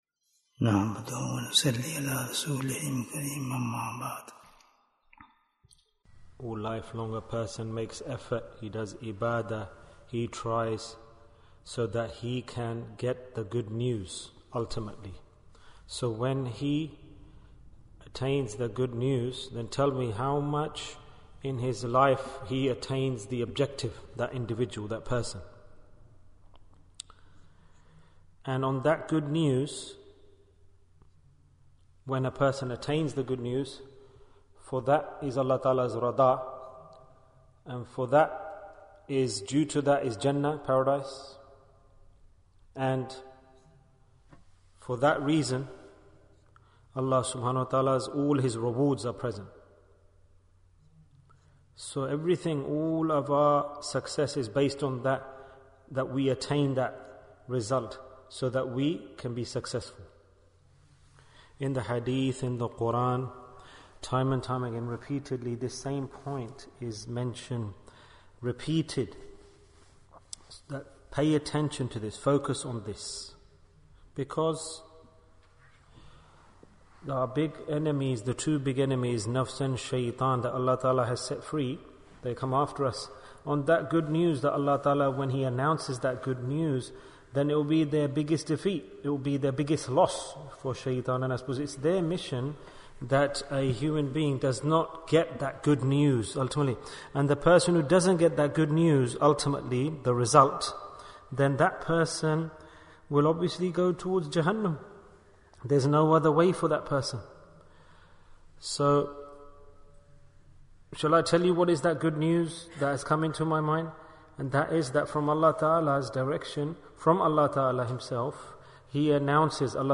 Forgiveness Bayan, 30 minutes14th March, 2023